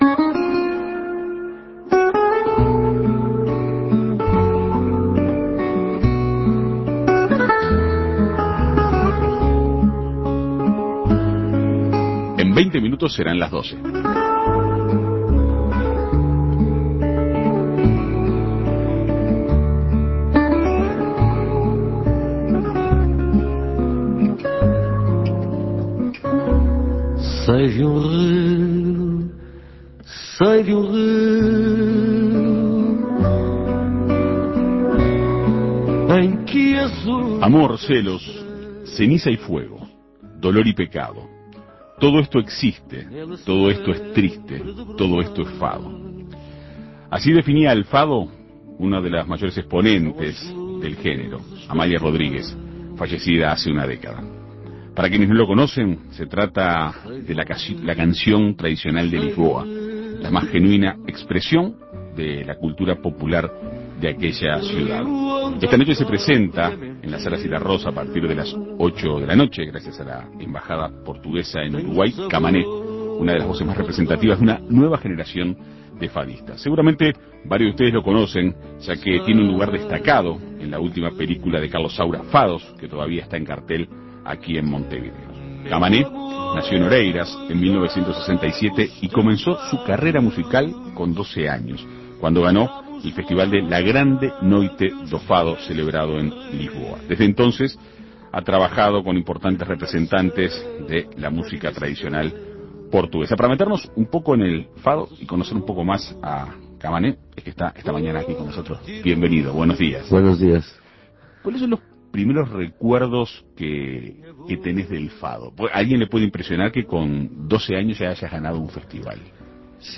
En Perspectiva Segunda Mañana dialogó con el artista para conocer más sobre este genero.